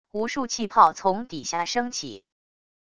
无数气泡从底下升起wav音频